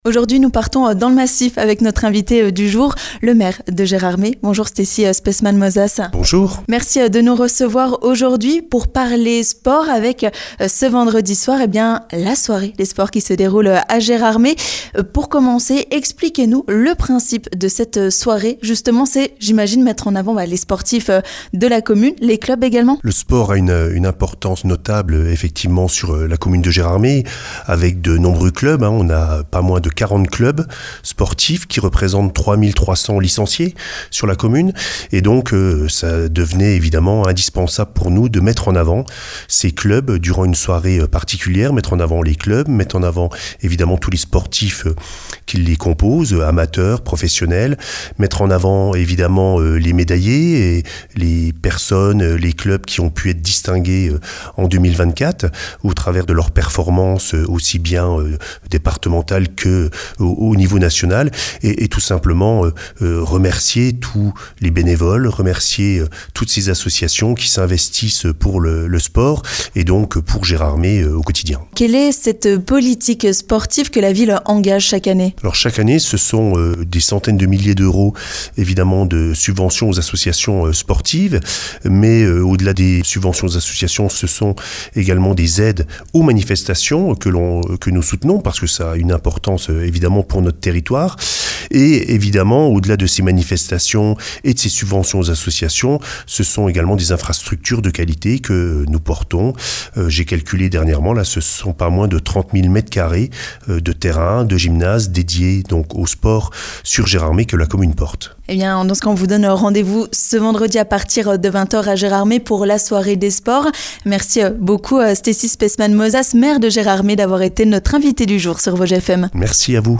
A l'occasion de la Soirée du Sport Gérômois, Vosges FM est allé à la rencontre du maire de Gérardmer, Stessy Speissmann-Mozas. Le but étant de revenir sur l'intérêt de cette cérémonie mais aussi pour parler de la politique municipale concernant le sport et les associations. Stessy Speissmann-Mozas, maire de Gérardmer est notre Invité du Jour.